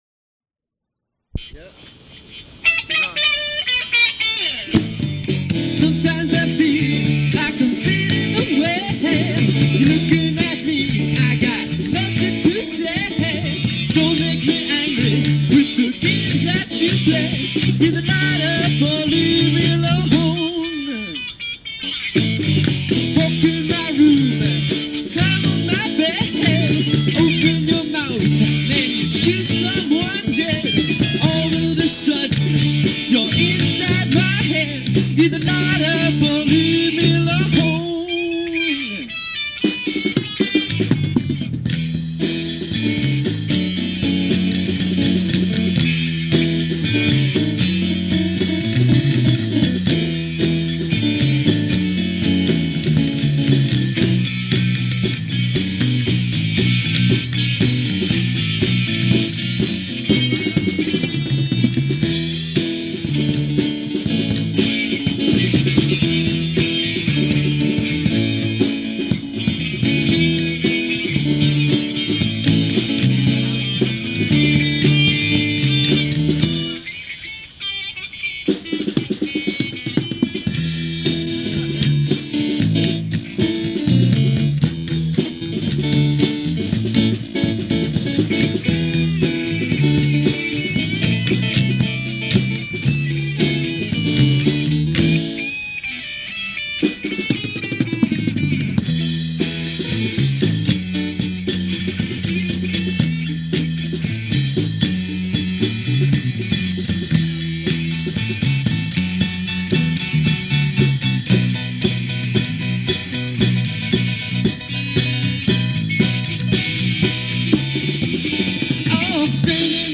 Afternoon Performance, November 07, 1981
percussion
bass guitar, vocals
keyboard